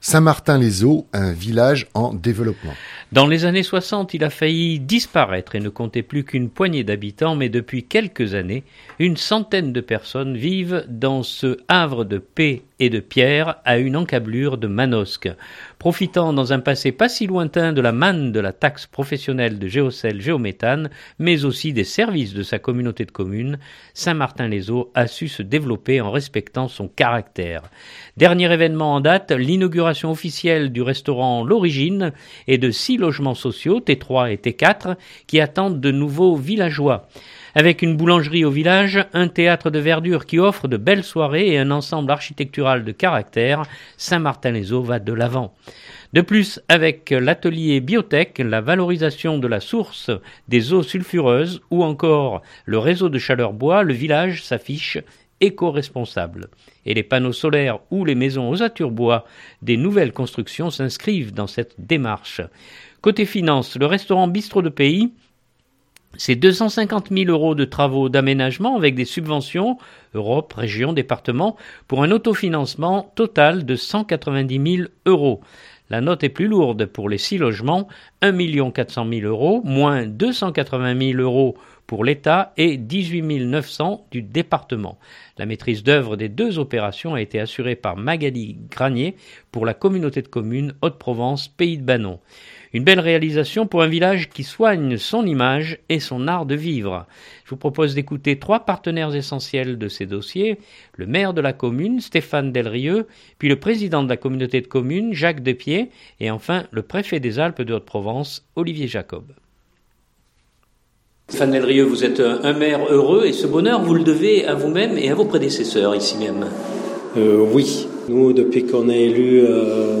2019-02-20-Reportage-Saint-Martin-Les-Eaux.mp3 (4.25 Mo)
Je vous propose d’écouter trois partenaires essentiels de ces dossiers : le maire de la commune, Stéphane Delrieu puis le président de la communauté de communes Jacques Despieds et enfin le préfet des Alpes de Haute-Provence Olivier Jacob.